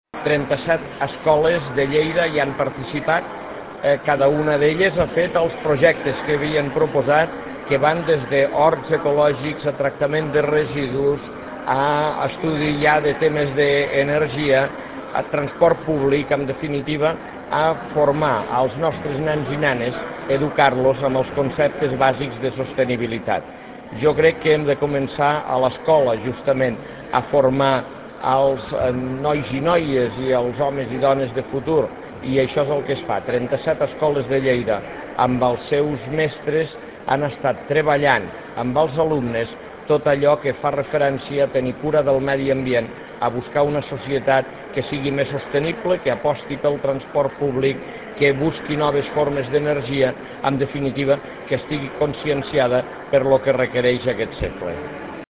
arxiu-de-veu-on-ros-destaca-els-aspectes-formatius-del-projecte